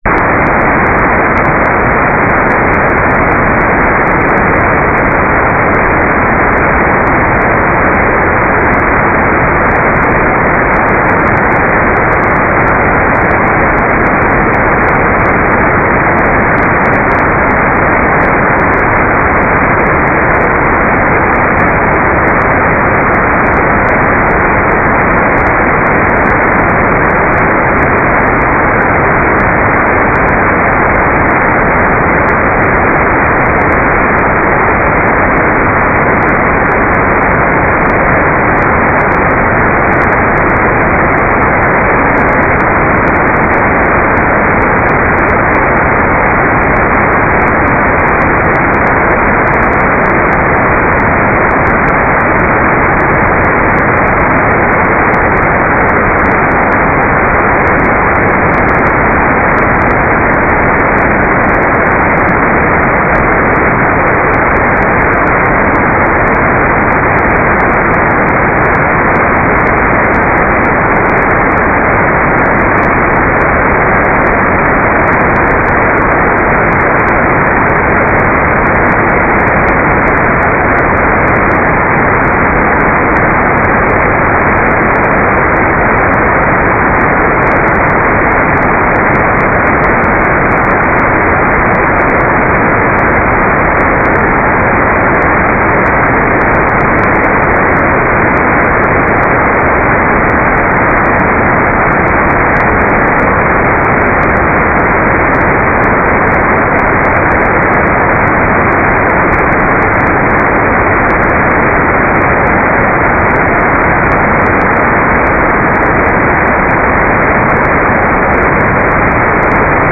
"waterfall_status": "without-signal",